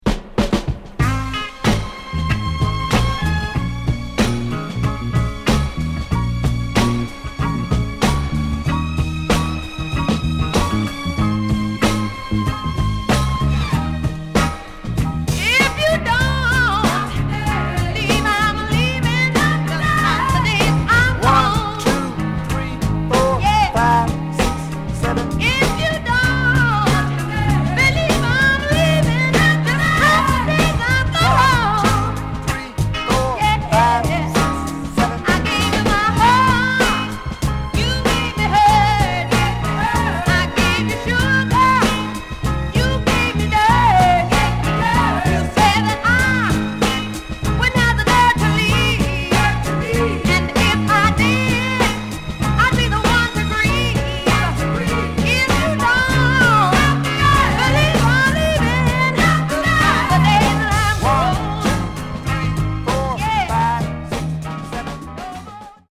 Genre: Other Northern Soul